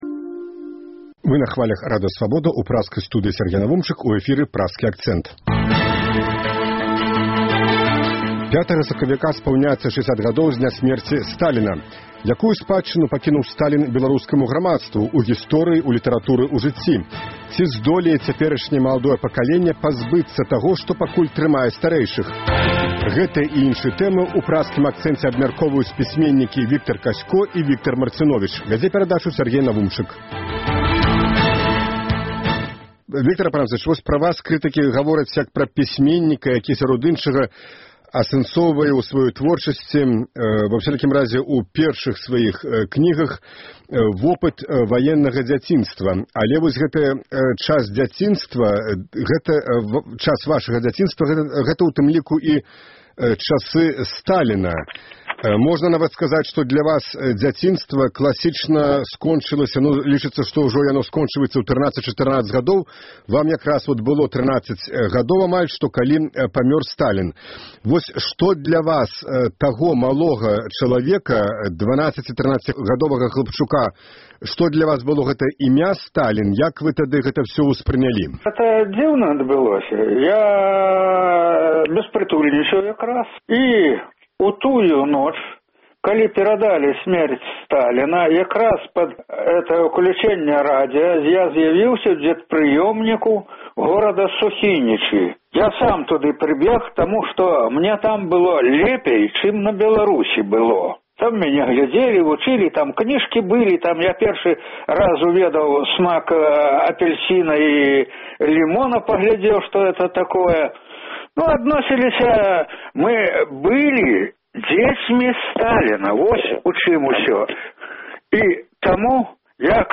Гэтыя і іншыя тэмы ў “Праскім акцэнце” абмяркоўваюць пісьменьнікі Віктар Казько і Віктар Марціновіч.